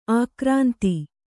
♪ ākrānti